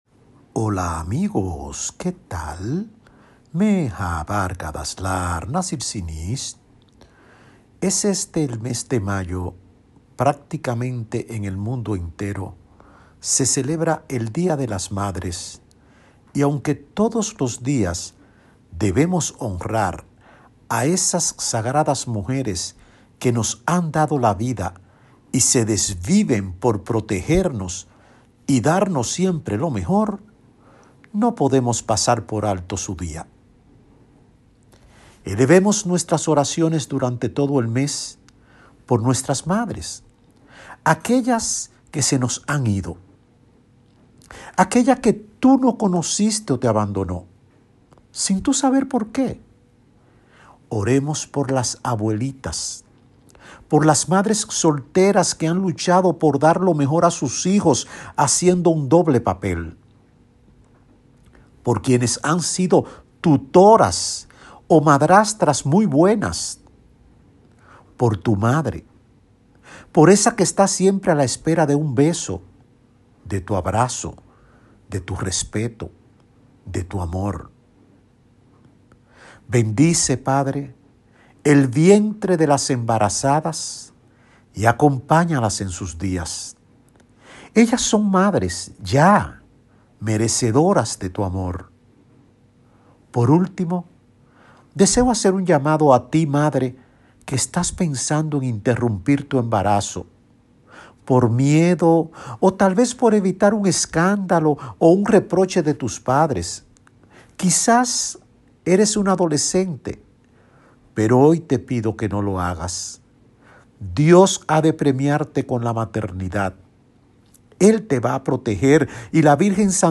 REFLEXIONES….